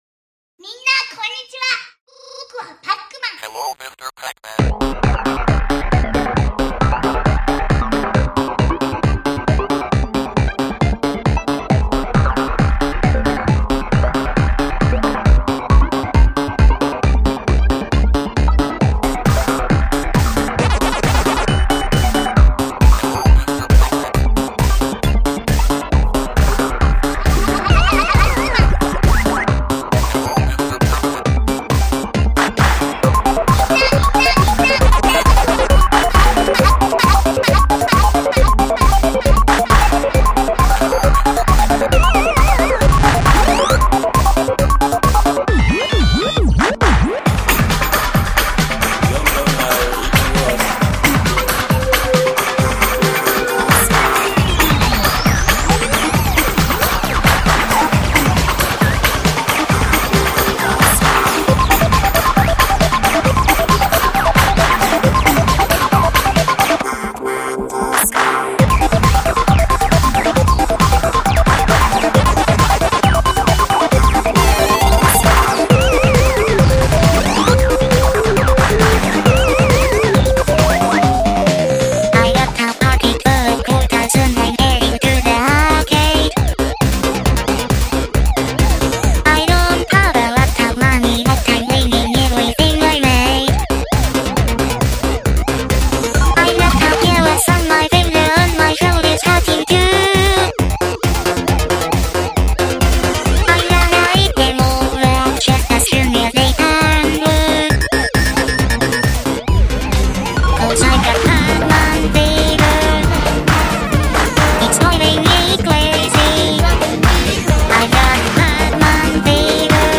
いつものやかましいアレンジですが
Digital Equipment Corporation DECTalk
Vocaloid Mac音ナナ, 巡音ルカ, Amy, Chris, CYBER DIVA, CYBER SONGMAN
NEUTRINO ずんだもん